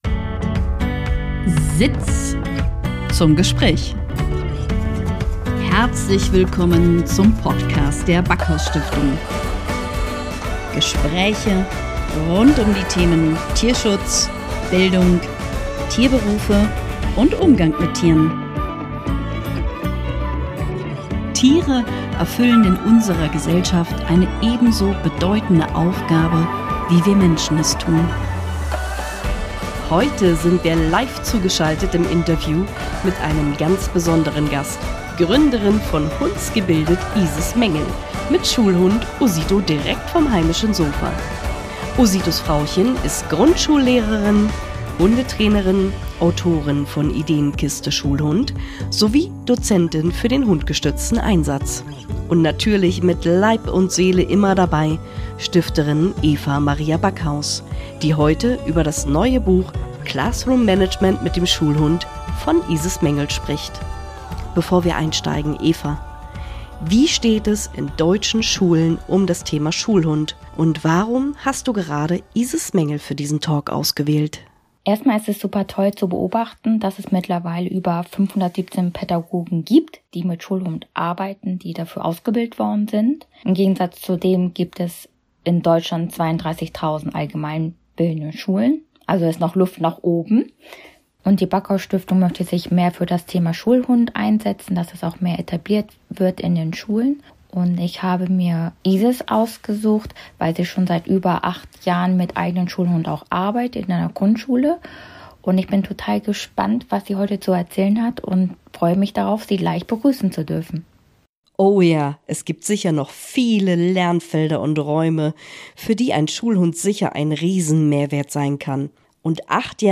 Beschreibung vor 3 Jahren Heute sind wir live zu geschaltet im Interview mit einem besonderen Gast